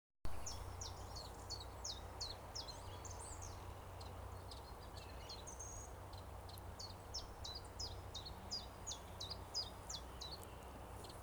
Птицы -> Славковые ->
теньковка, Phylloscopus collybita
Administratīvā teritorijaRīga